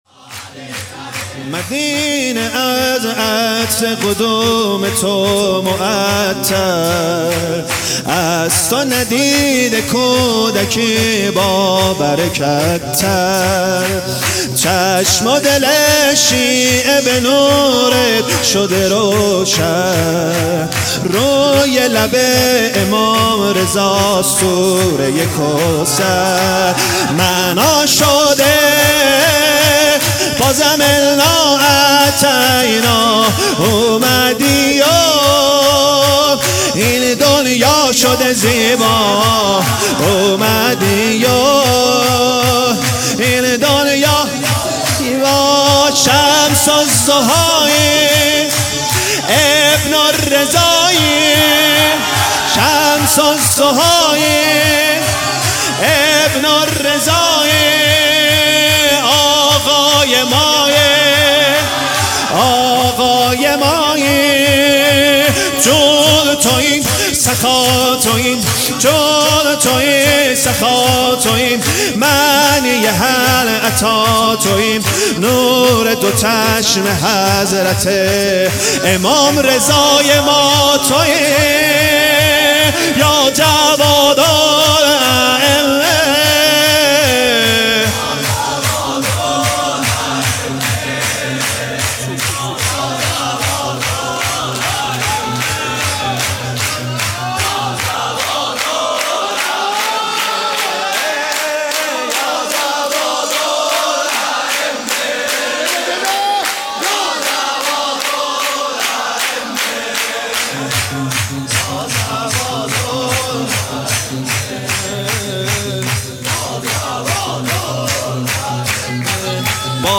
سرود | مدینه از عطر قدوم تو معطر
ولادت امام جواد الائمه(ع)